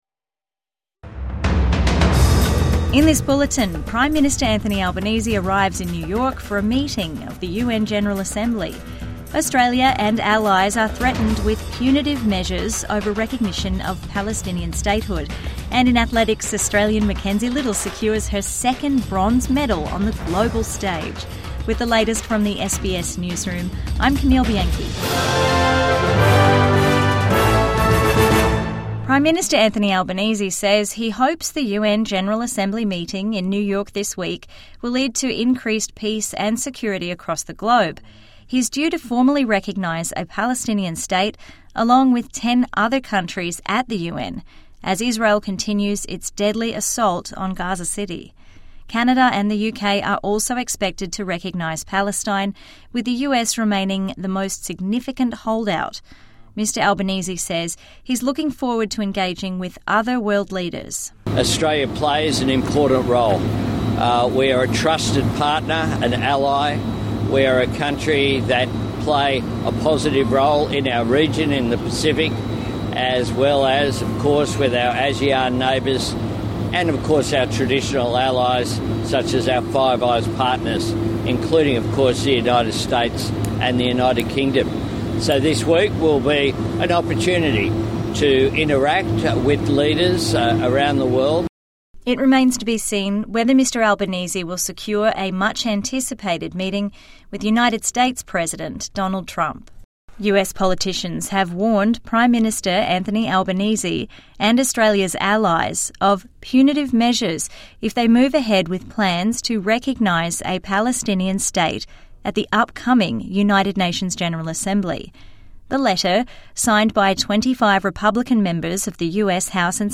Albanese in New York for UN meeting | Midday News Bulletin 21 September 2025